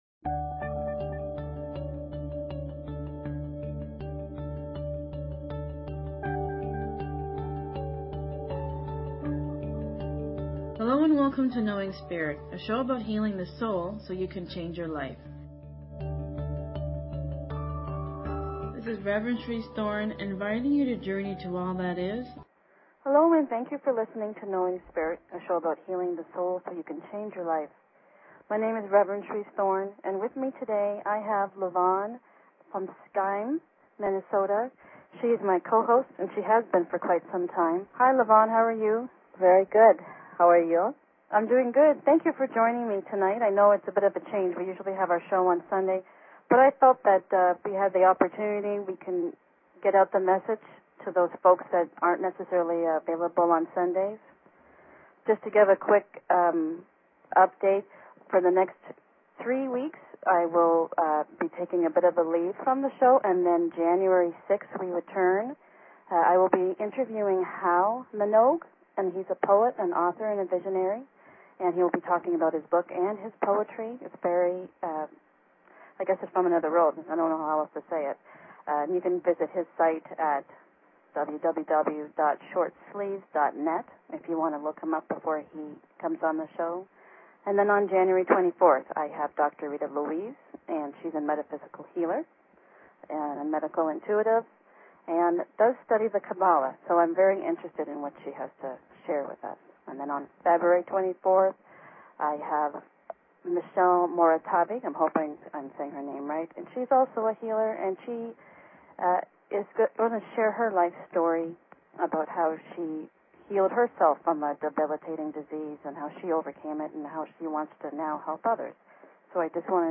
Show Headline Knowing_Spirit Show Sub Headline Courtesy of BBS Radio Knowing Spirit - December 8, 2007 Knowing Spirit Please consider subscribing to this talk show.